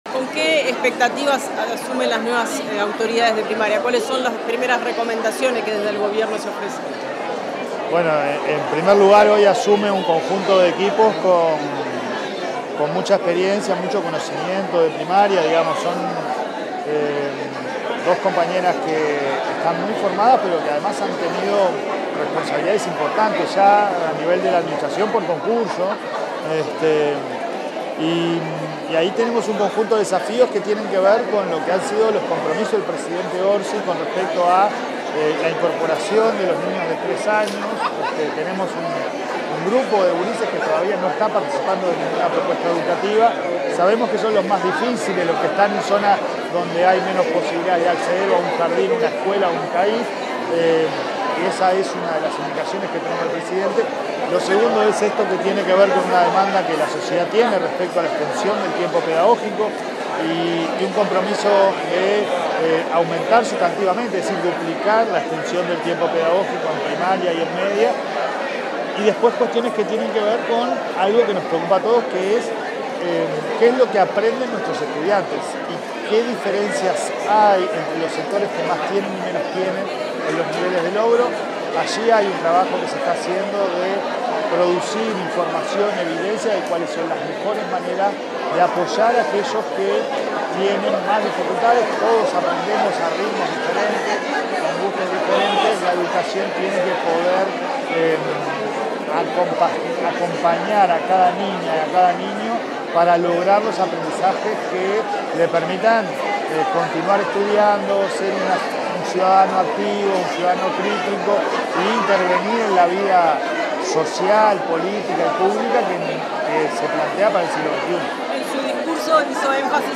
Declaraciones del presidente de ANEP, Pablo Caggiani
Declaraciones del presidente de ANEP, Pablo Caggiani 28/03/2025 Compartir Facebook X Copiar enlace WhatsApp LinkedIn Tras participar en la ceremonia de asunción de las autoridades de la Dirección General de Educación Inicial y Primaria, este 28 de marzo, el presidente de la Administración Nacional de Educación Pública (ANEP), Pablo Caggiani, realizó declaraciones a la prensa.
Caggiani prensa.mp3